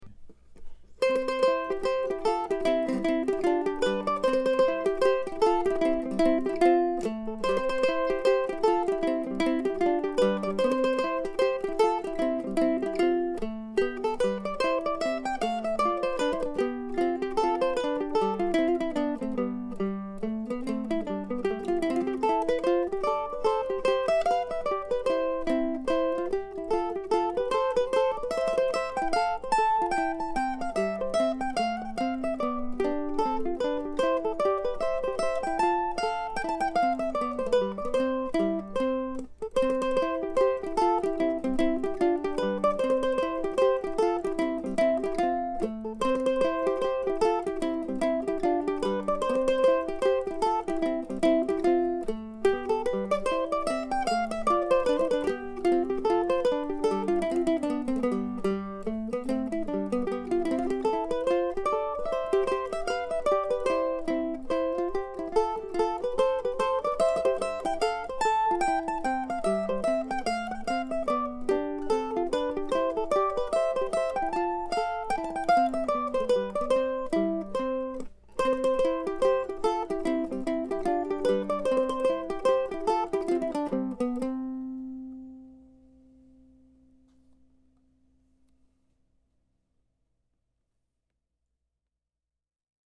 Another tune that began its life in 2005, now revisited and improved for a digital debut as a mandolin duo in 2014.